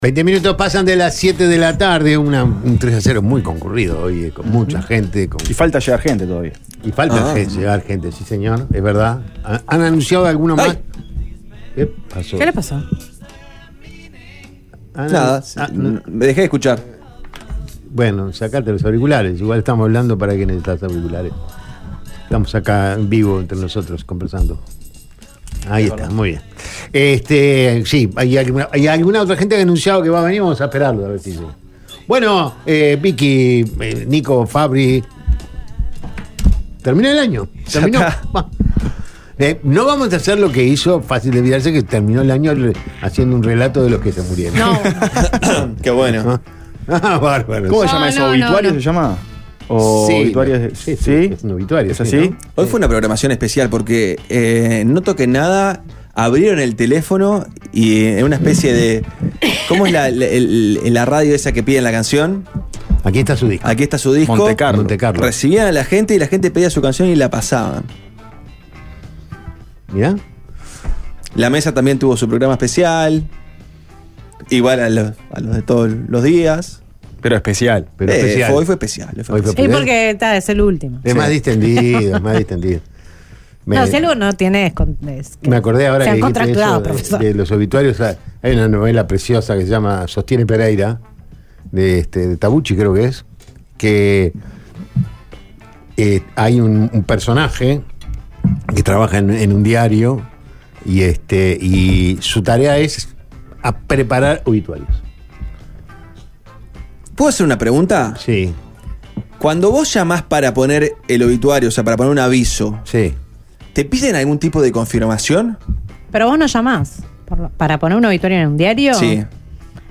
Periodístico deportivo